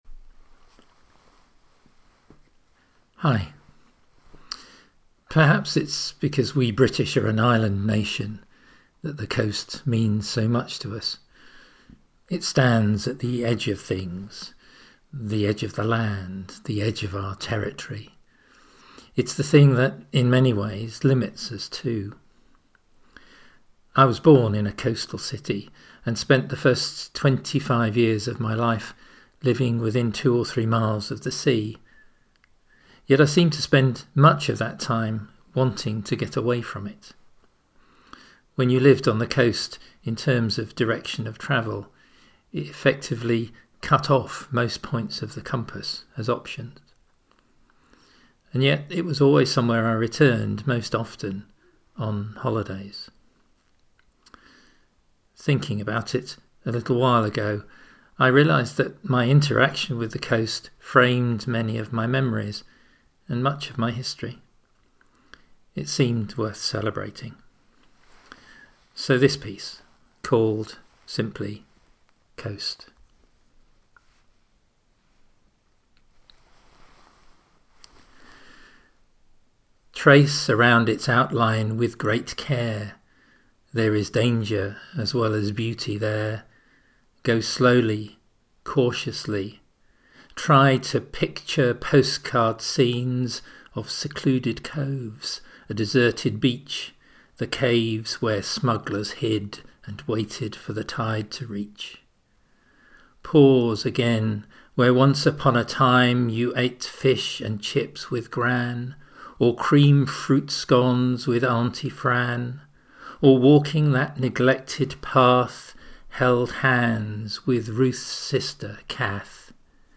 Here is a reading of my poem, ‘Coast’.